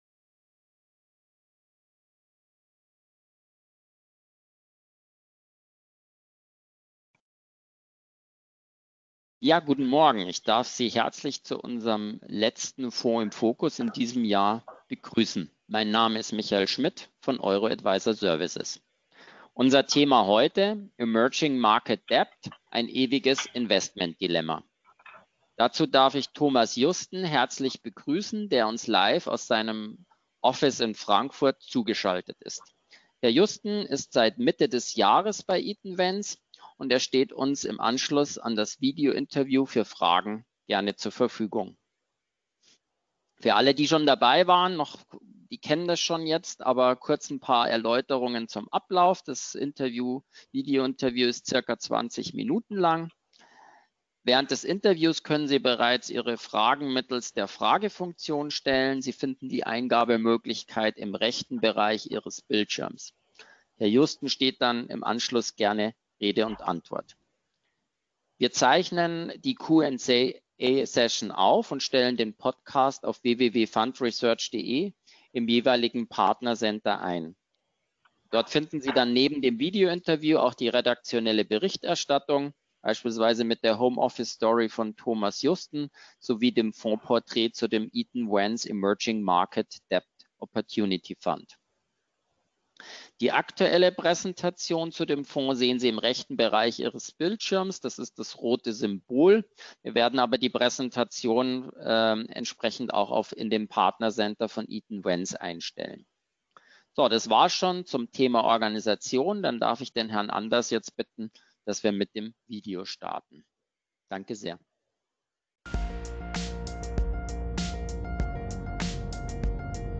Audio zum Webinar am 11.12.2020 mit Eaton Vance